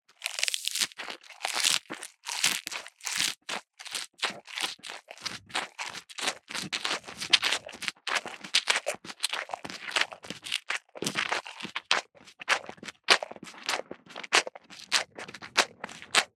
جلوه های صوتی
دانلود صدای یونجه خوردن گاو از ساعد نیوز با لینک مستقیم و کیفیت بالا